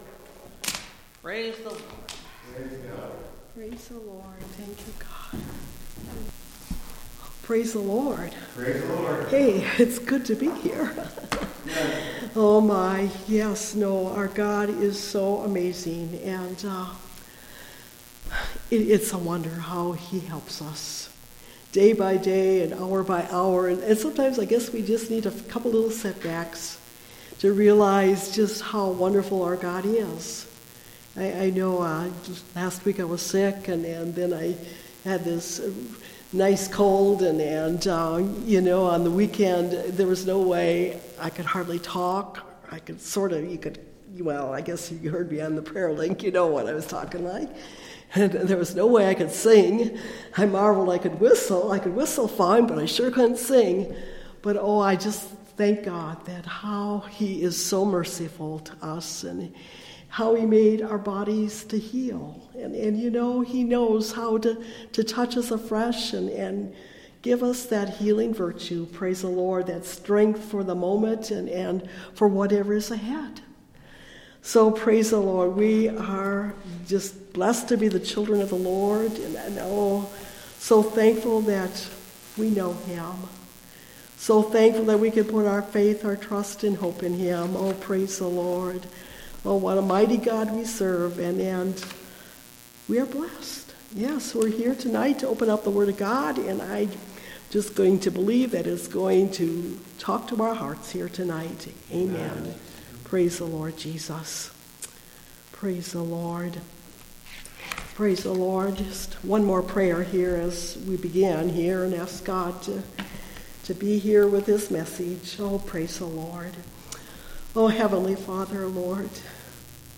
He Hath Appointed A Day (Message Audio) – Last Trumpet Ministries – Truth Tabernacle – Sermon Library